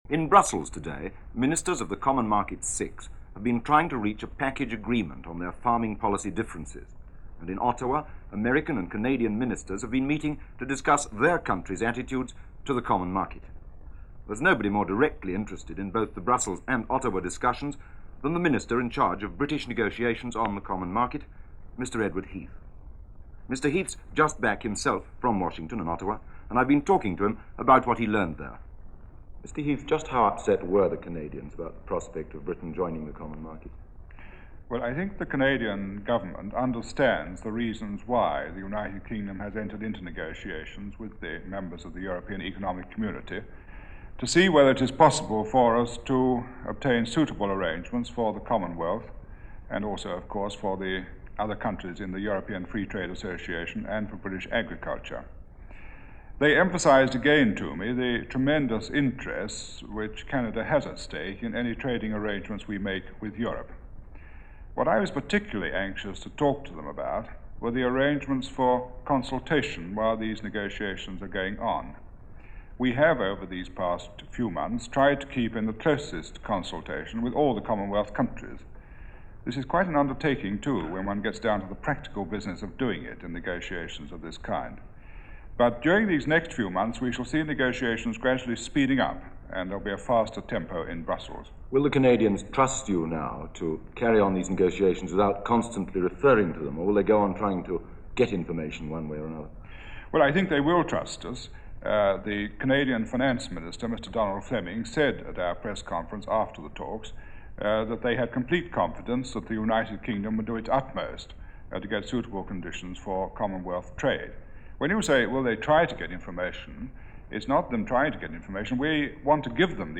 Before it was the EU is was known as The Common Market - BBC reports and interviews from January 1962.
Interviews and reports – BBC World Service